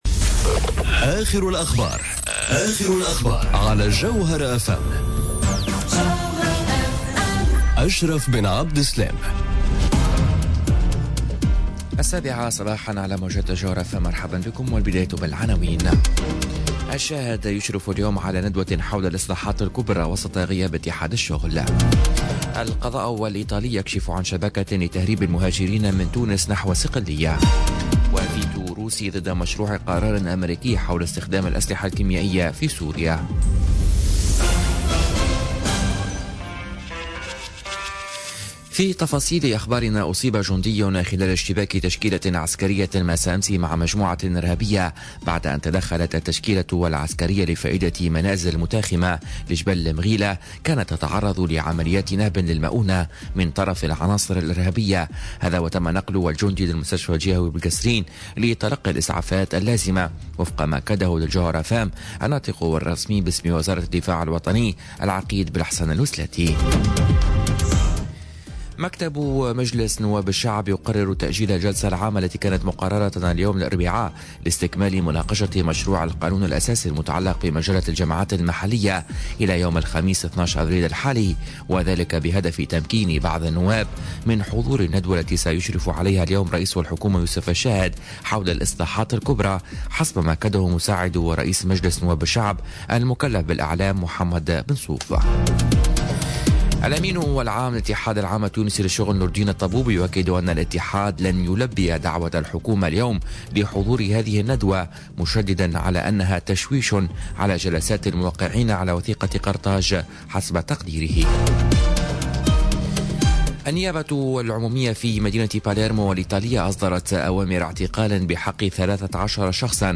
نشرة أخبار السابعة صباحا ليوم الإربعاء 11 أفريل 2018